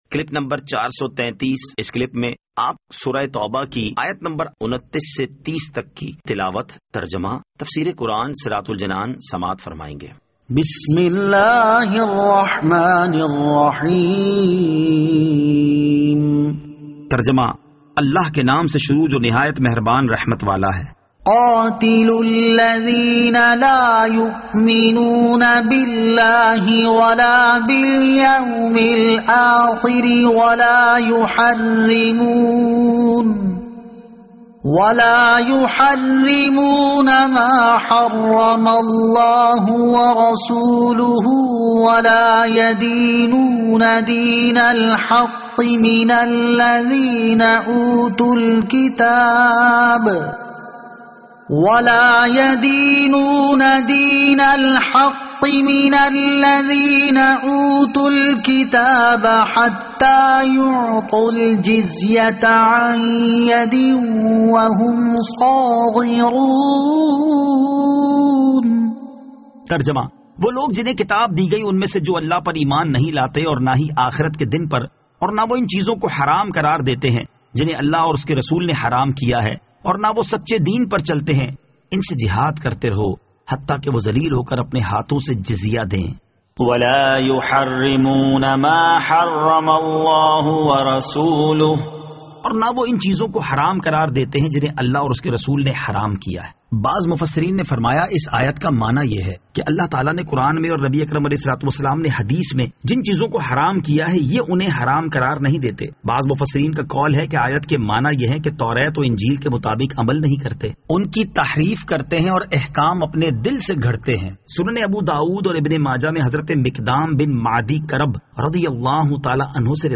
Surah At-Tawbah Ayat 29 To 30 Tilawat , Tarjama , Tafseer